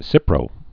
(sĭprō)